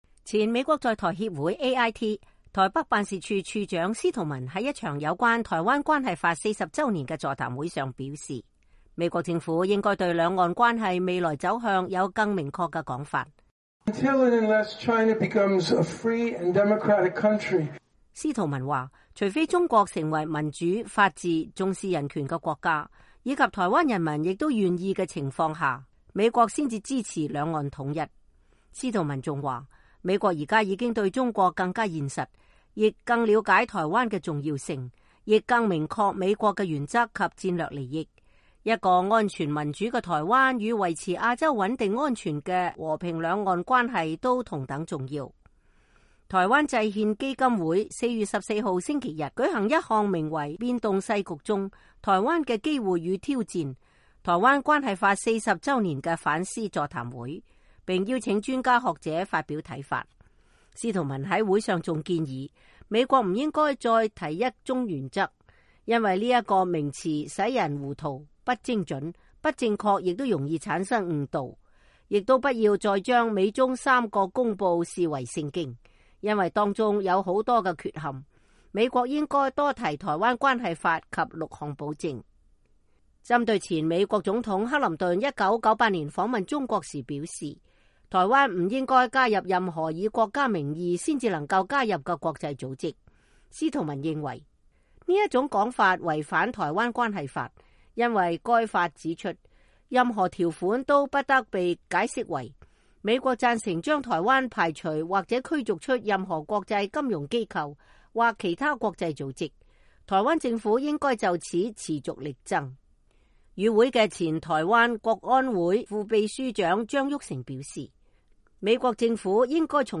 今年是台灣關係法40週年，前美國在台協會台北辦事處處長司徒文在一場座談會上表示，美國政府應該對兩岸關係未來走向有更明確的說法。